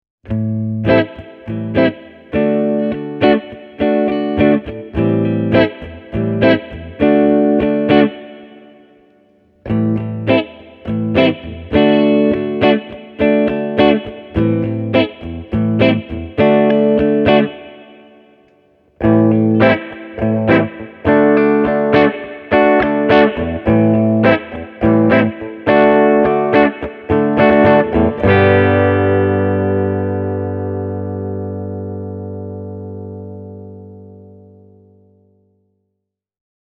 Myös PAF-tyylinen humbucker soi kauniisti Ampegin kautta:
SG – puhdas
sg-e28093-clean.mp3